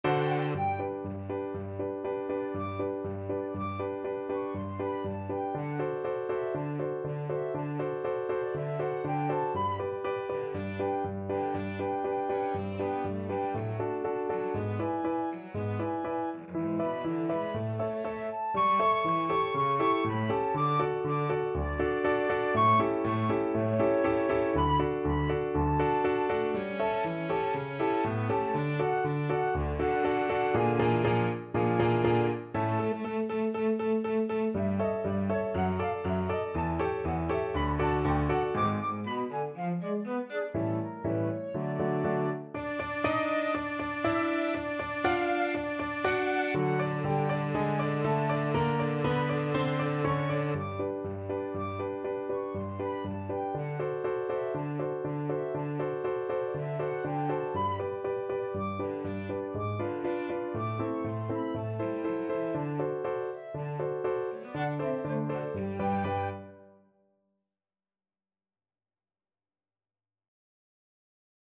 FluteCelloPiano
Molto allegro
2/4 (View more 2/4 Music)
Classical (View more Classical Flute-Cello Duet Music)